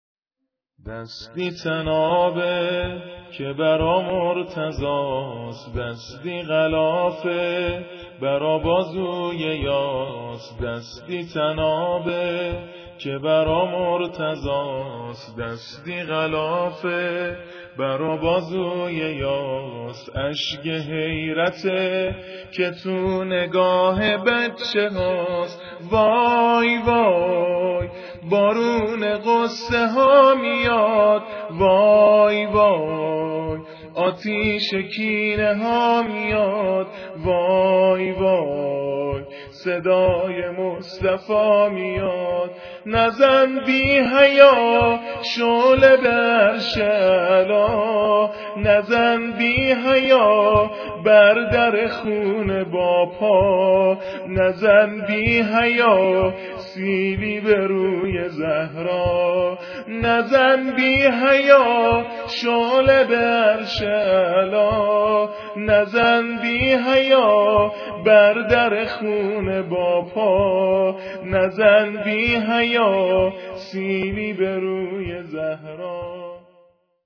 نوحه با سبک دهه فاطمیه